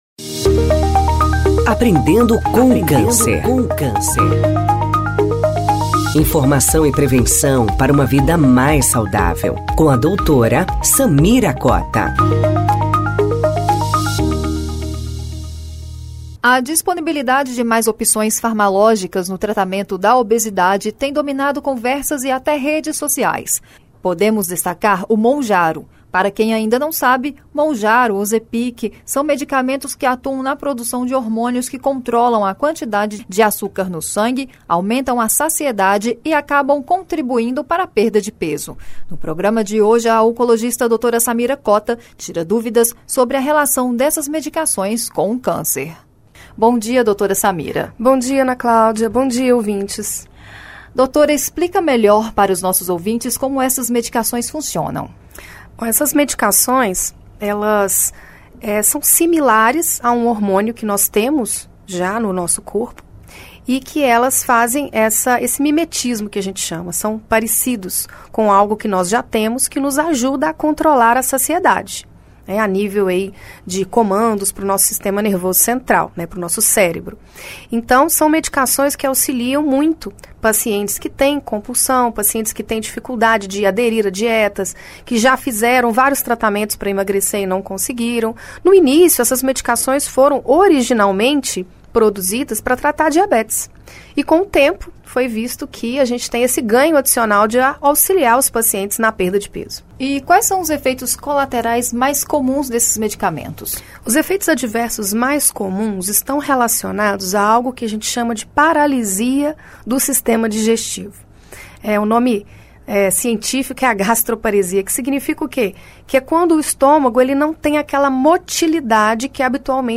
No programa desta semana, a oncologista